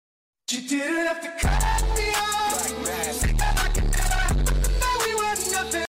Galaxy brain meme sound effect sound effects free download